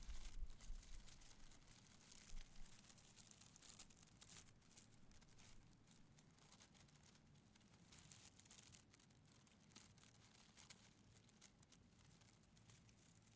Ruffling through branches with hand
Duration - 13 s Environment - Open air, waterfall, rivers, streams, mountains, light breeze and cars passing. Description - Ruffing, hitting, scraping, branches, fluctuate.